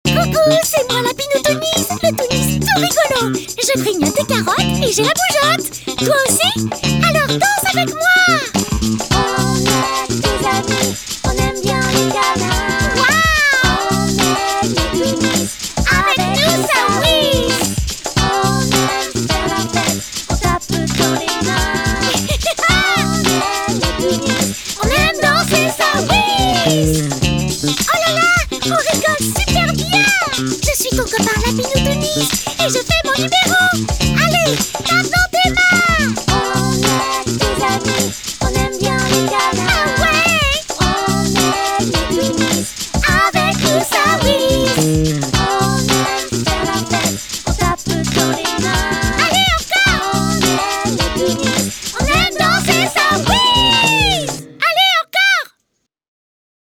Chanson Lapinou Toonies (Voix cartoon peluche maquette)
Chant
Chanteuse